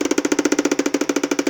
Play, download and share Terkotanie original sound button!!!!
terkotanie.mp3